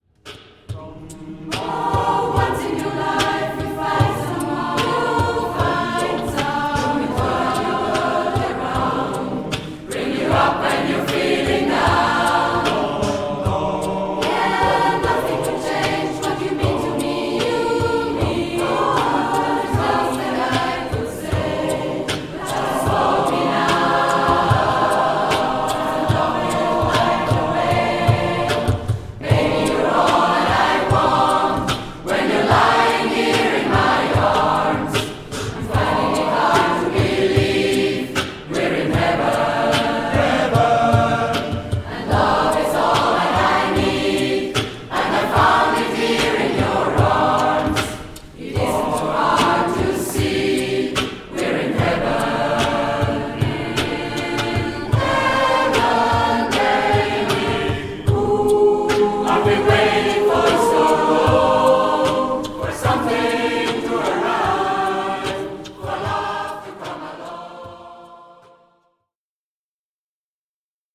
a Cappella
SMATB